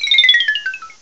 cry_not_kricketot.aif